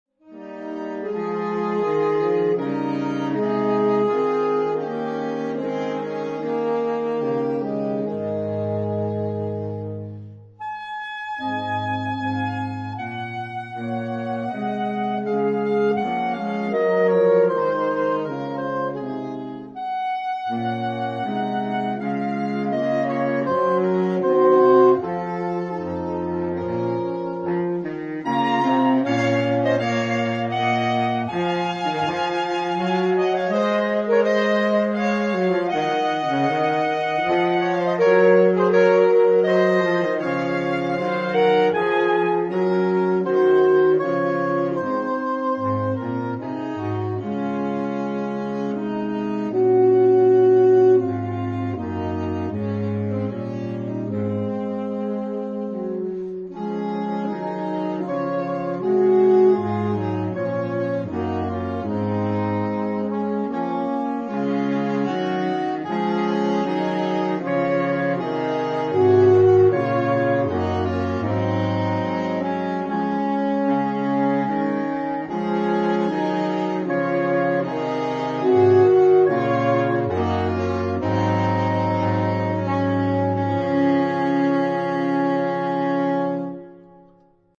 Gattung: für Blechbläserquartett
Besetzung: Ensemblemusik für 4 Blechbläser